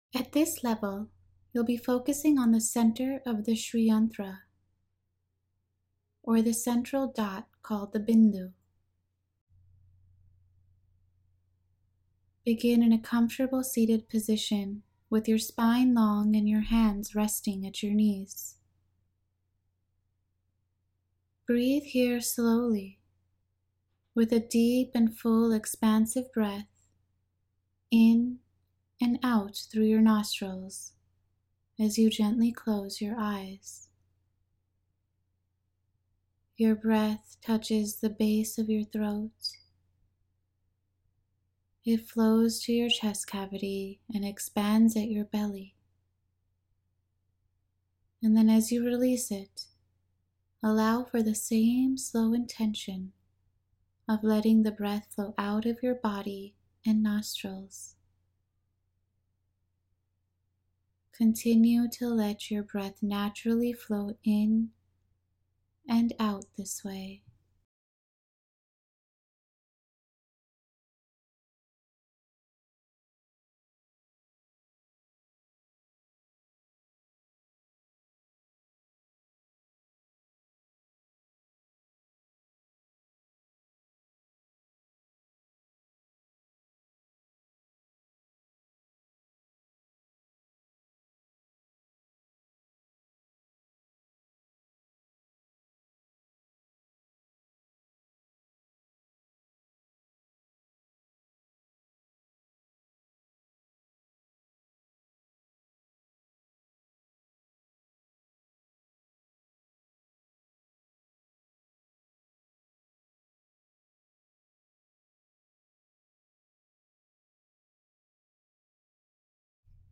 Day 9 Meditation The Cosmic Creation Guided Meditation You MUST use stereo headphones or earbuds for the Holosync®/alpha brain wave patterns (the background track) to be effective.
To make these meditations even more powerful and effective, we've added, beneath the spoken voice, world-famous Holosync ® audio technology (licensed from Centerpointe Research Institute). These slightly audible Holosync ® audio tones will place you in a deep meditative (and super-receptive) alpha brain wave pattern --expanding your awareness and your openness, and allowing these meditations to affect you at an even deeper level.